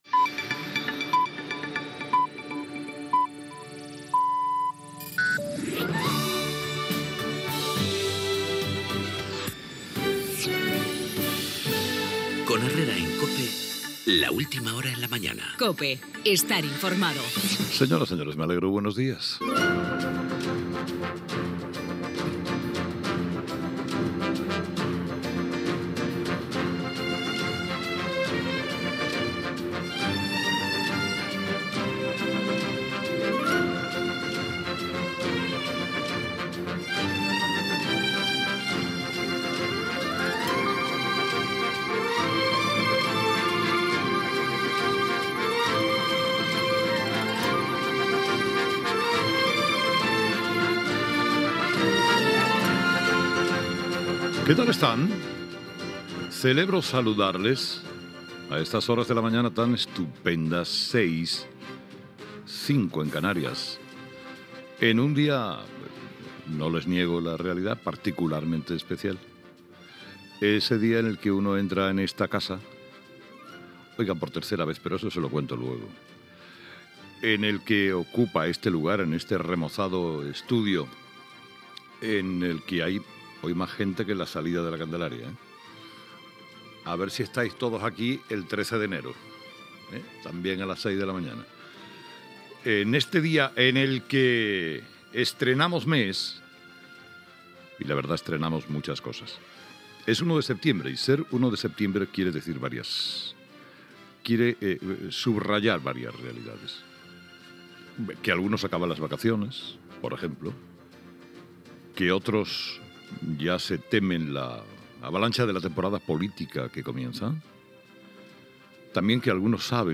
Careta del programa, sintonia, hora, presentació de la primera edició del programa, exposant els seus objectius, tema musical i record als programes "España en pijama" a l'any 1983 i a "La mañana" l'any 1992, hora i identificació
Info-entreteniment Presentador/a Herrera, Carlos